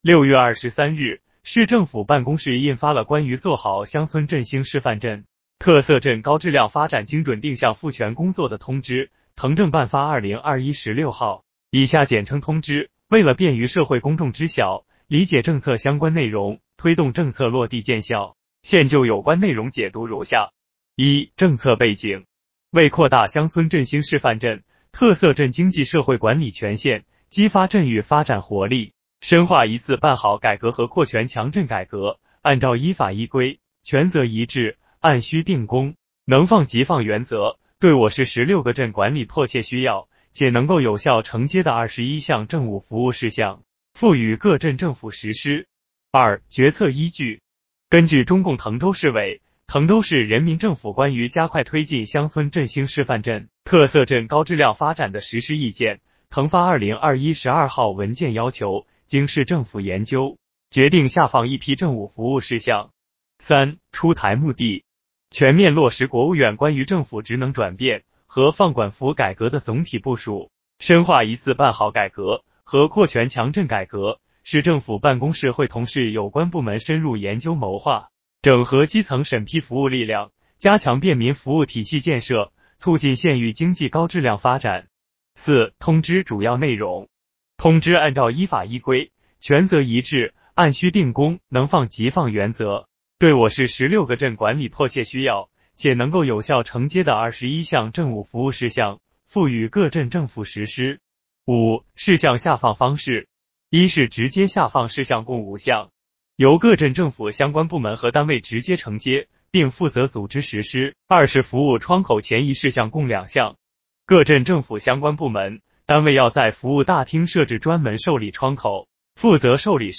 【音频解读】《关于做好乡村振兴示范镇、特色镇高质量发展精准定向赋权工作的通知》